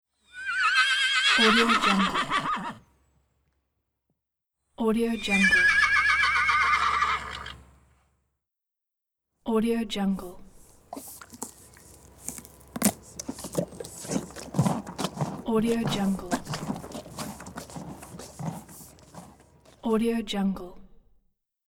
Horse Bouton sonore